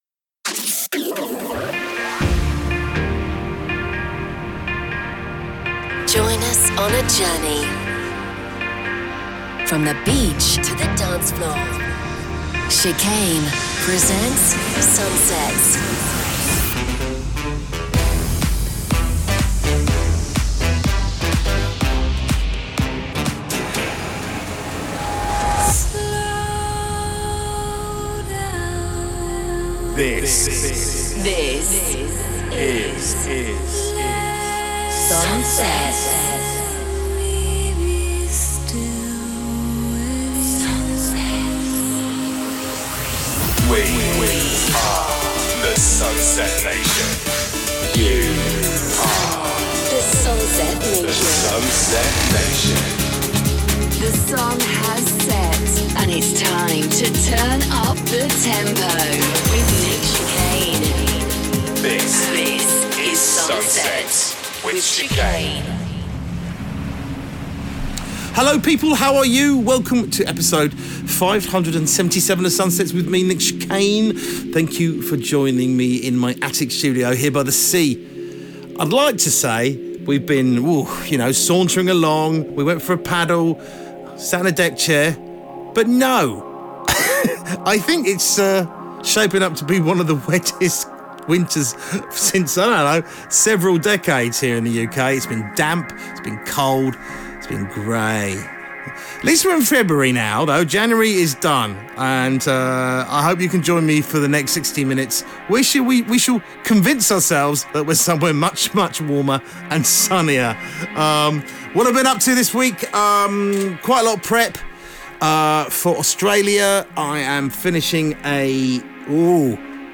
music DJ Mix in MP3 format
Genre: Progressive house; Duration